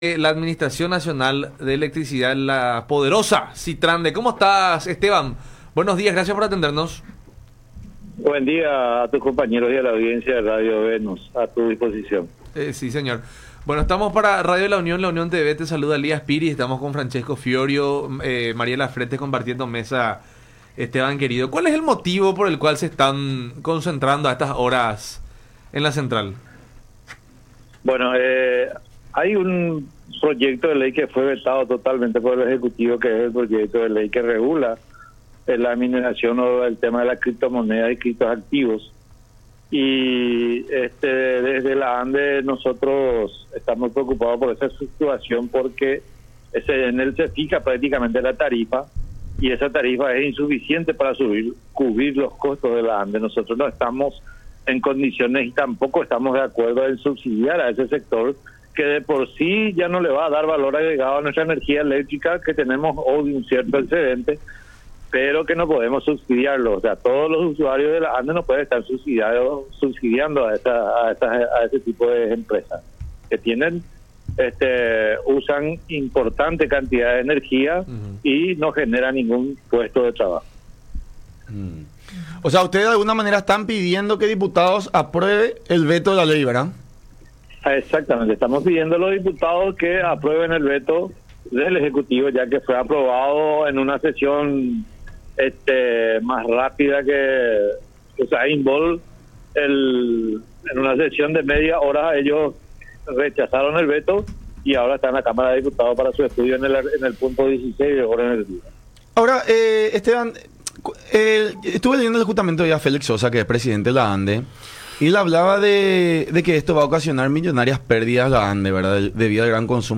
en diálogo con La Unión Hace La Fuerza por Unión TV y radio La Unión